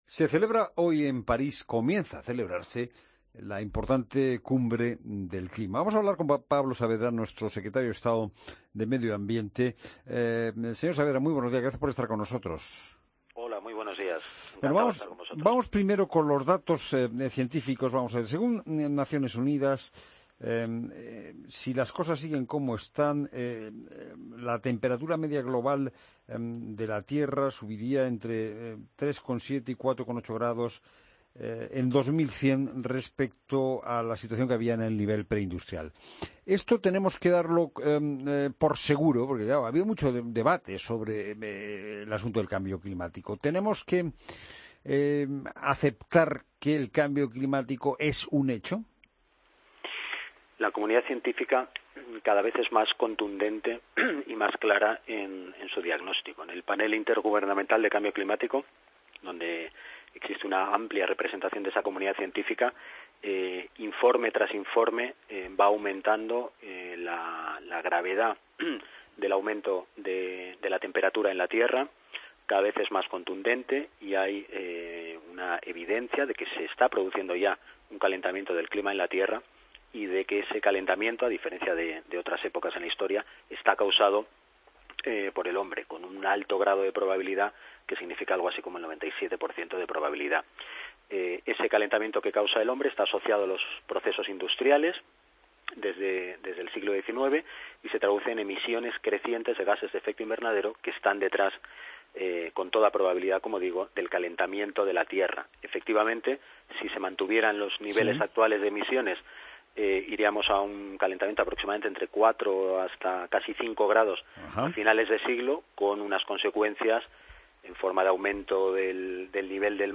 Entrevista a Pablo Saavedra, Secretario de Estado de Medio Ambiente, sobre la Cumbre del clima de Paris en La Mañana del Fin de Semana